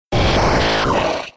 Cri d'Abra K.O. dans Pokémon X et Y.